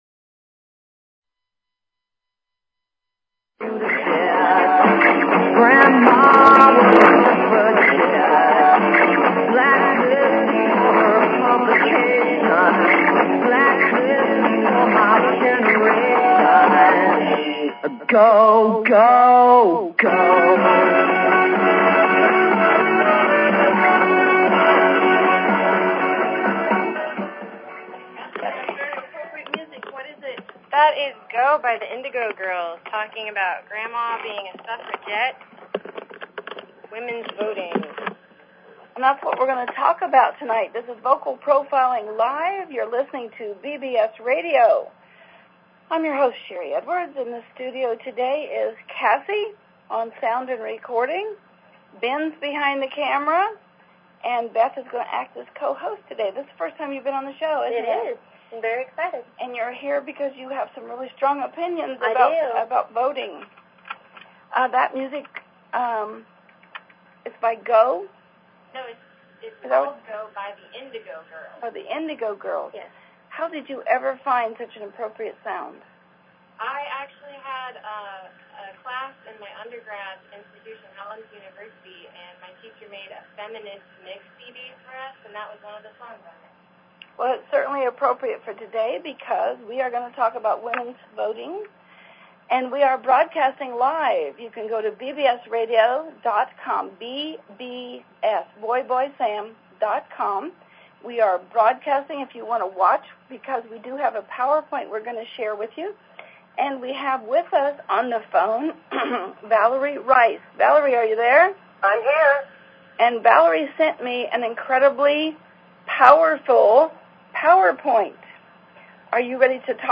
Talk Show Episode, Audio Podcast, Vocal_Profiling_Live and Courtesy of BBS Radio on , show guests , about , categorized as
Several women discussed whether or not they were voting and their motivations, who they were voting for, and why it is important to vote.